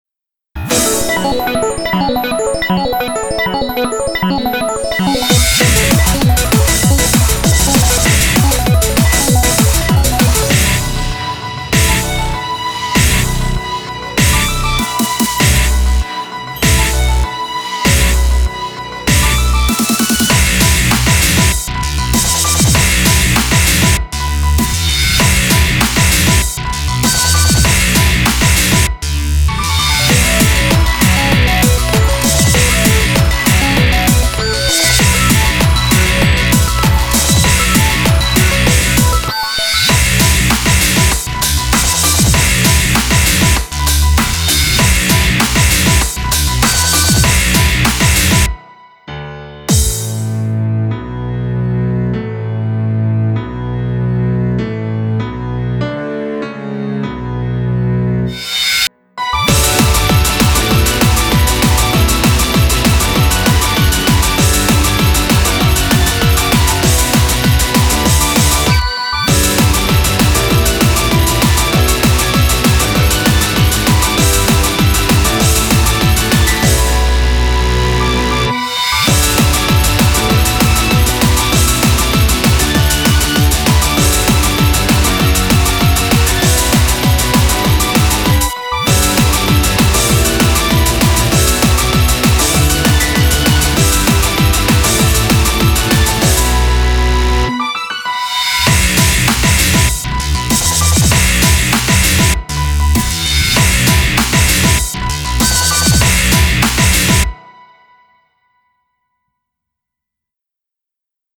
BPM98-196
Audio QualityPerfect (High Quality)
darker than your usual